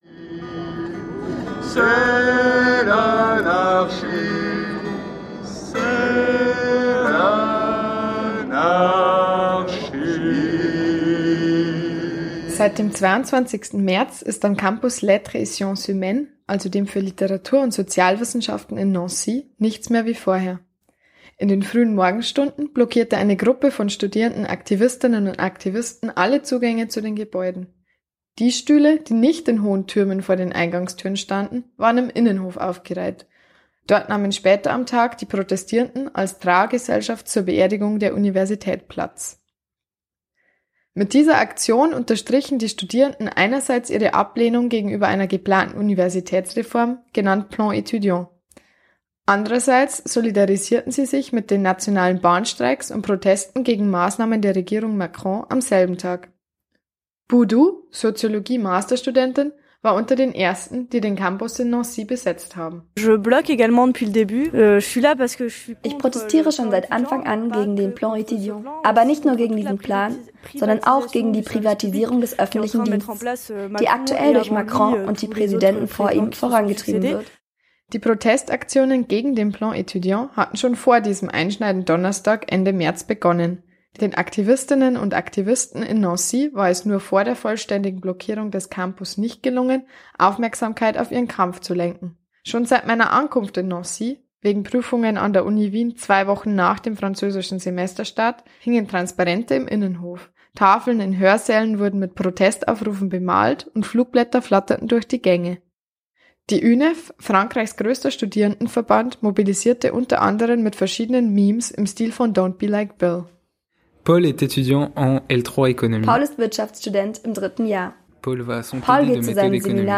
Er wird auch im Uniradio Campus Wien ausgestrahlt, aber weil ich so viel Arbeit hineingesteckt habe (und damit ich den Überblick behalte), veröffentliche ich ihn auch hier bei Lieblings-Plätzchen.